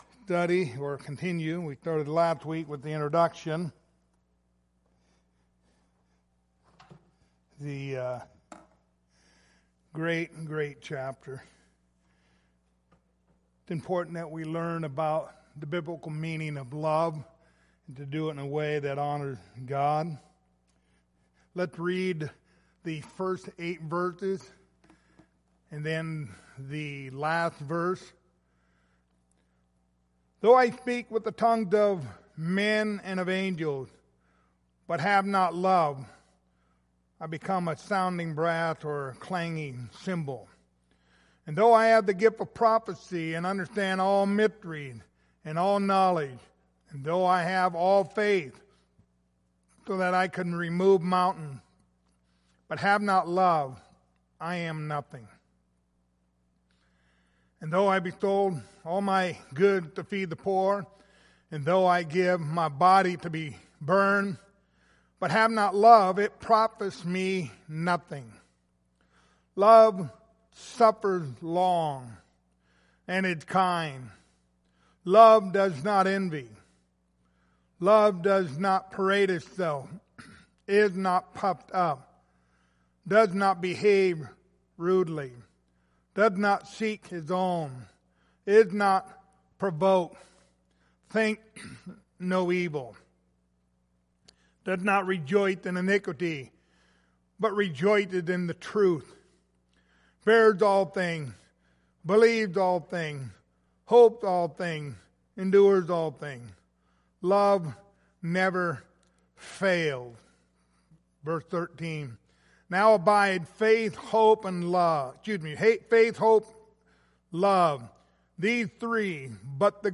Passage: 1 Corinthians 13:1-8, 13 Service Type: Wednesday Evening